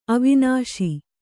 ♪ avināśi